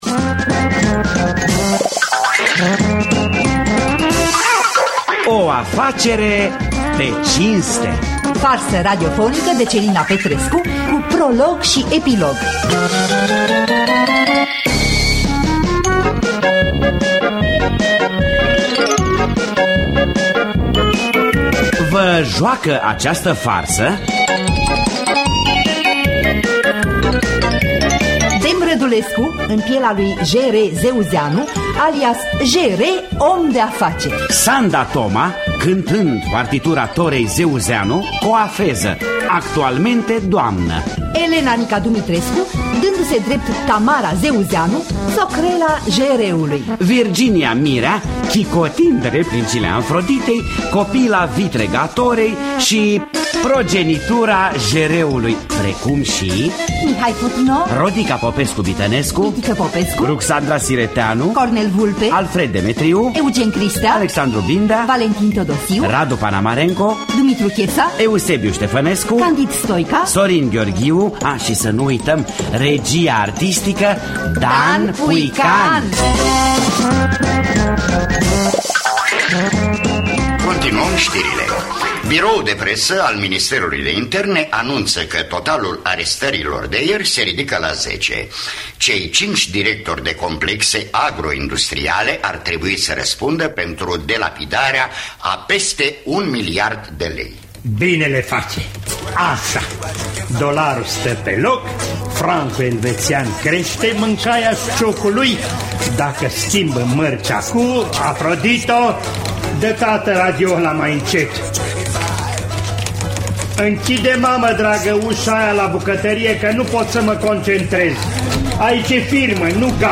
O afacere pe cinste! de Celina Rotar Petrescu – Teatru Radiofonic Online